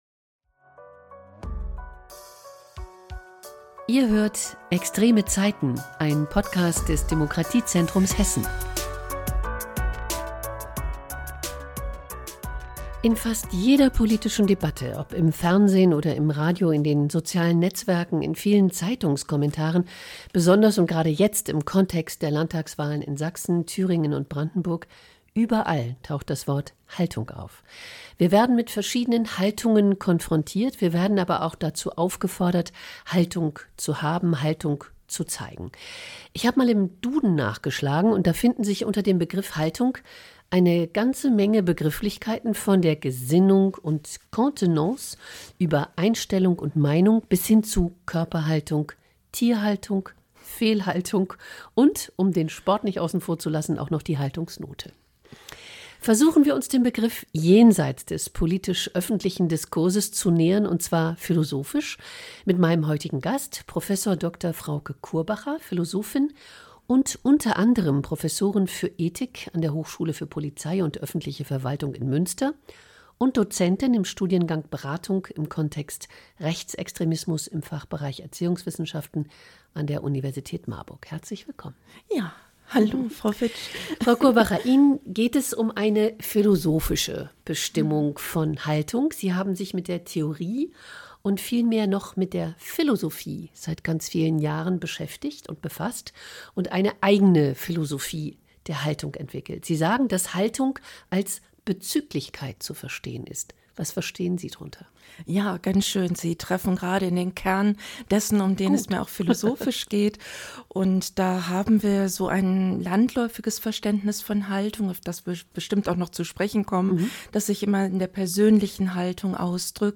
Ergänzend zu diesem Interview